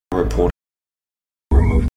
One of mine is the bunched or molar r, particularly the clear variety heard in Lancashire, the Caribbean and traditional New York City.
Making a more considered evaluation, the pitch range seems right, the sibilants sound right, and spectrographic analysis suggests that those rʼs have about the same meeting-point of F2 and F3 as in the known recordings of Craig from my earlier post (roughly 1500 Hz). Here are some smaller bits of Craig and stormtrooper, respectively, so you can make your own ear-comparisons.